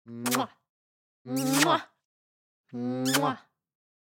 Звуки поцелуев
Звук где люди делают вид, что целуют в щеку